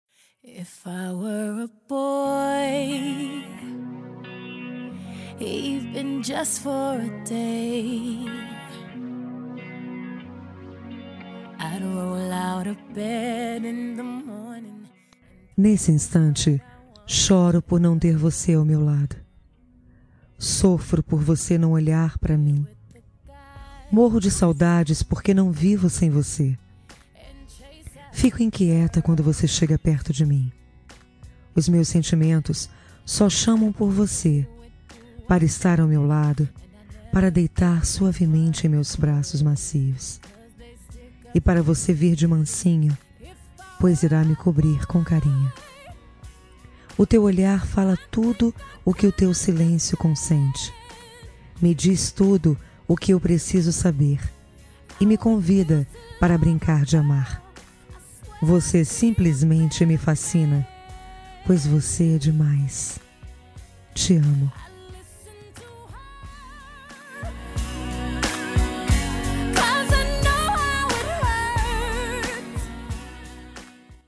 Dia Dos Namorados Voz Feminina